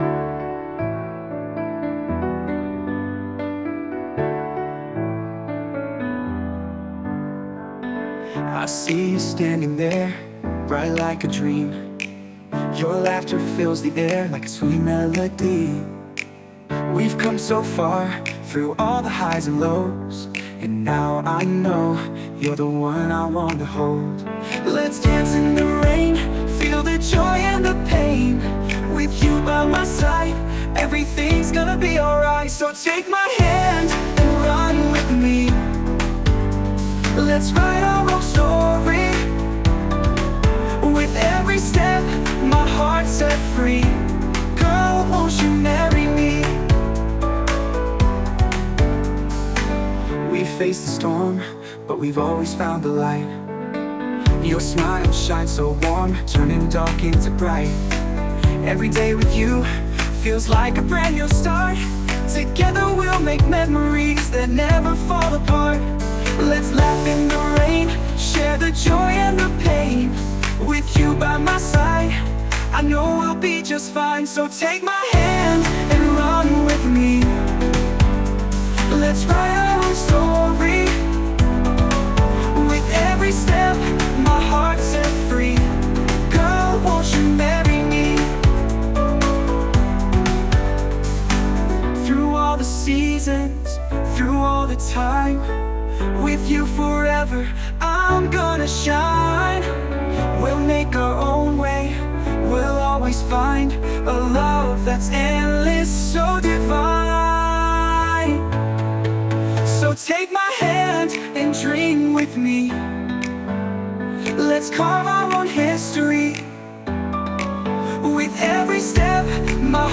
洋楽男性ボーカル著作権フリーBGM ボーカル
著作権フリーBGMです。
男性ボーカル（洋楽・英語）曲です。